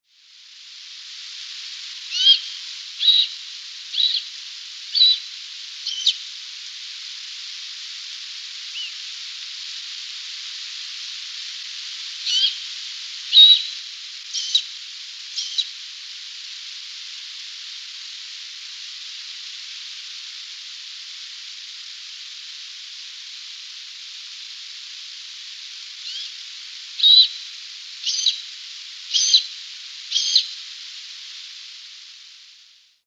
Águila Solitaria Aguilucho Cola Rojiza
Solitary Eagle Rufous-tailed Hawk